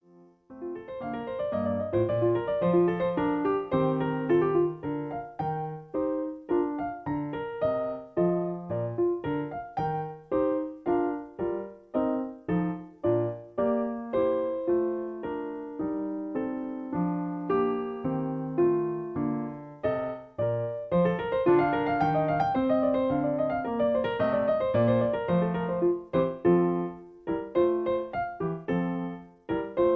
In B flat. Piano Accompaniment